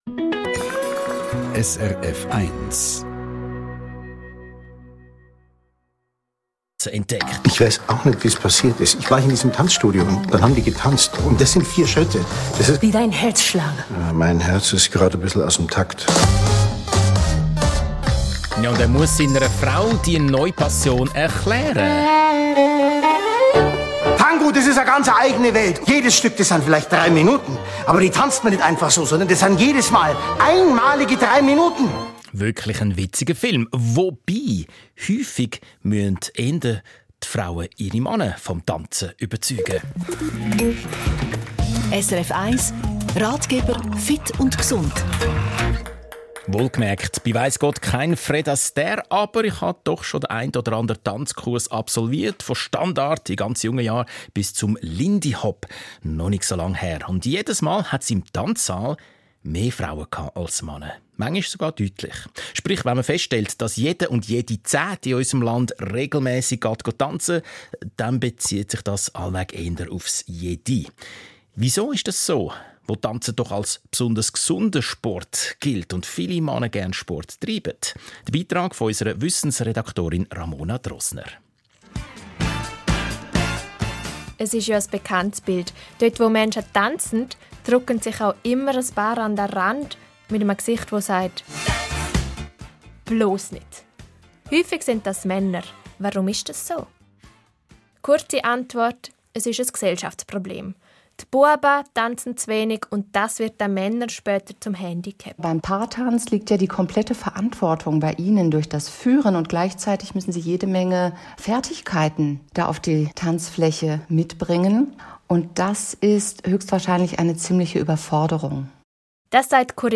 Audio aus der Schweizer Radiosendung PULS vom 22.8.2024